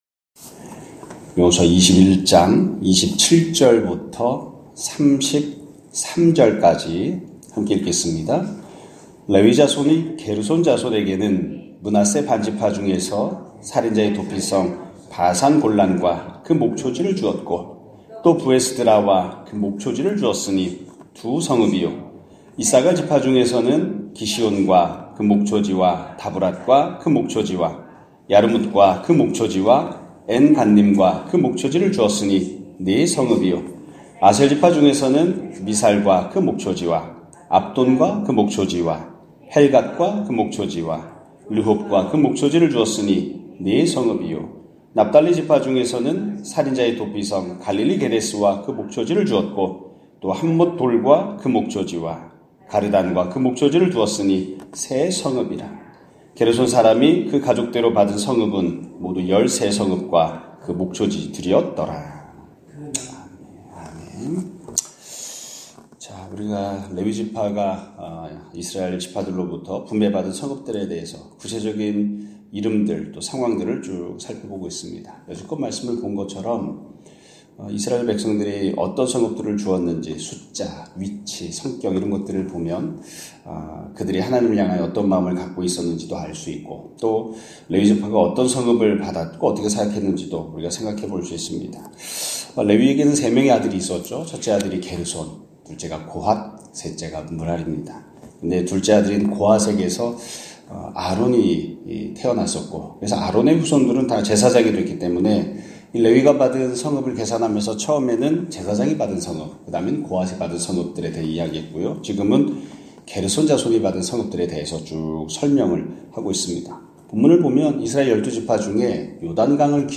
2025년 1월 23일(목요일) <아침예배> 설교입니다.